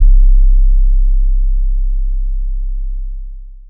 YM Sub 6.wav